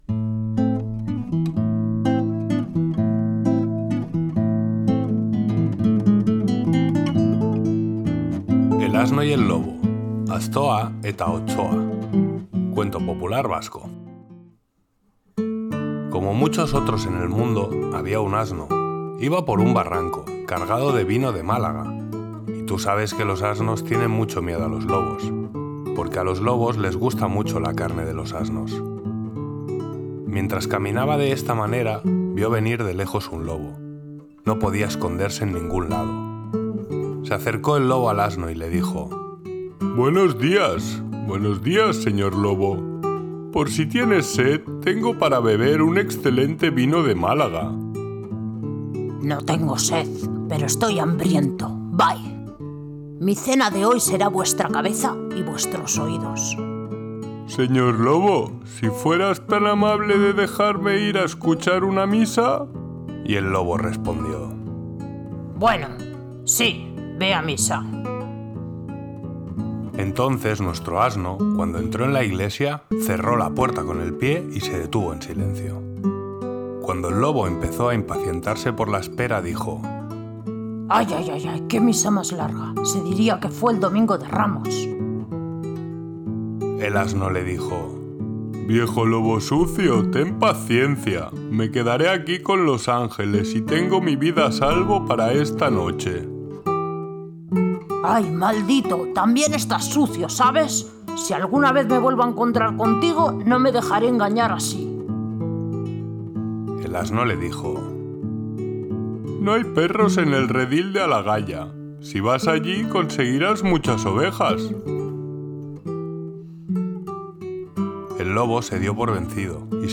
Música de guitarra clásica
Sátiras y cuentos cómicos